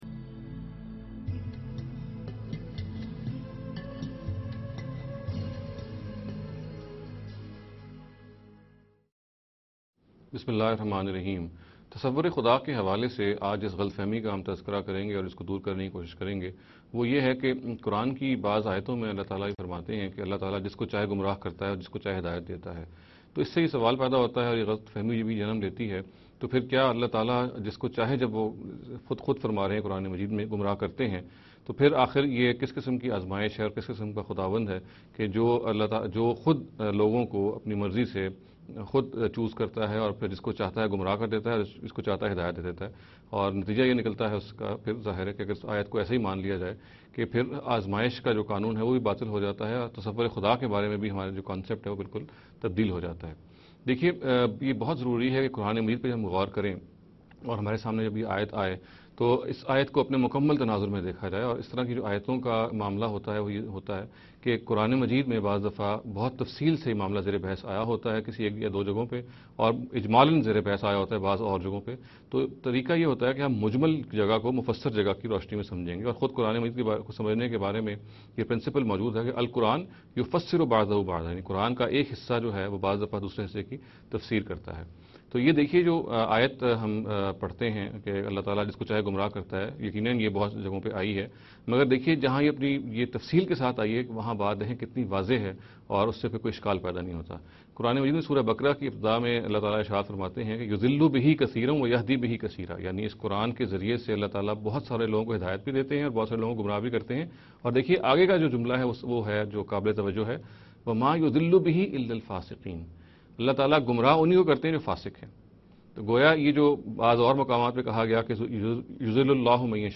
This lecture series will deal with some misconception regarding the concept of God. In every lecture he will be dealing with a question in a short and very concise manner.